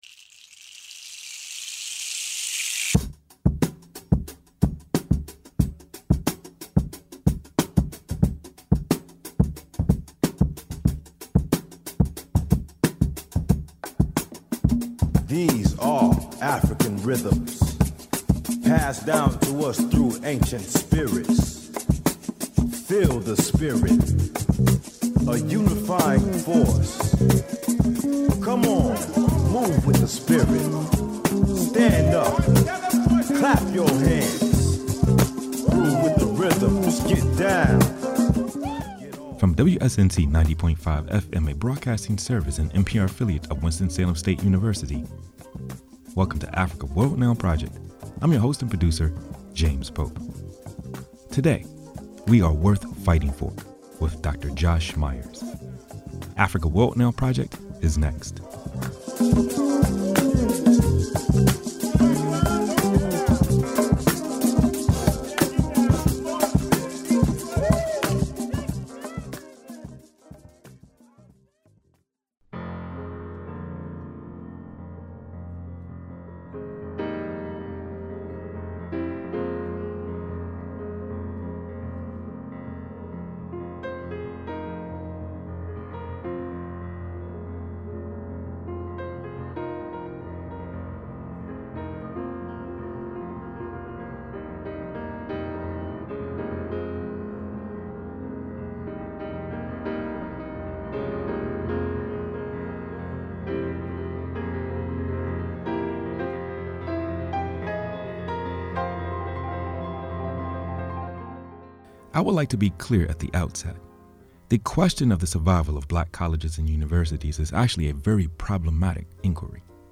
Africa World Now Project (AWNP Radio) is an educational multimedia program